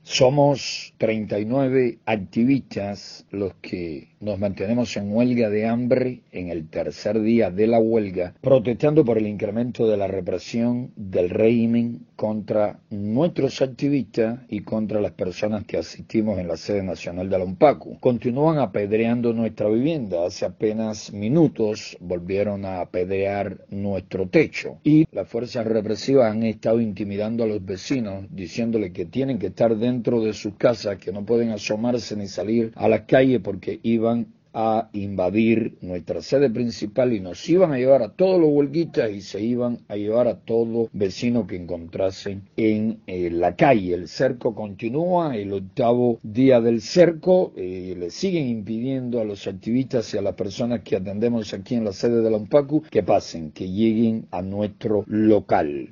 José Daniel Ferrer conversa con Radio Martí el lunes en la noche